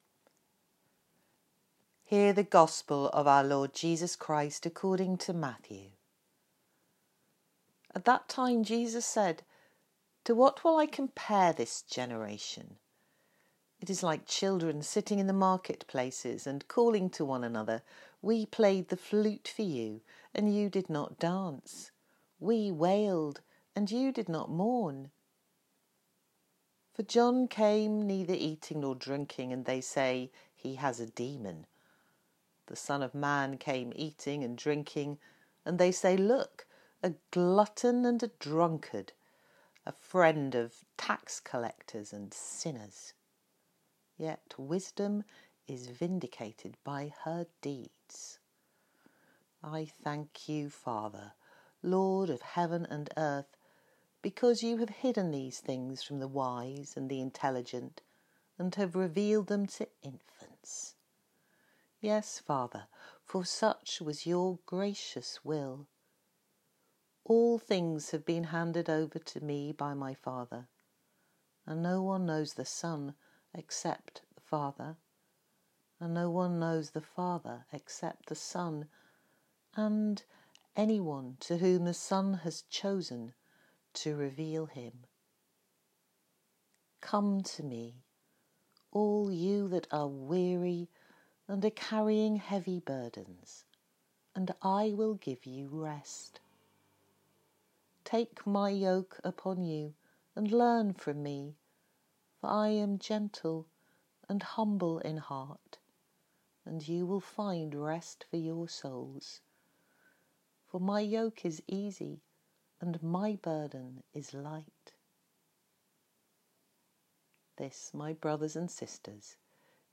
Gospel Reading
Gospel-Reading-5-July-2020.m4a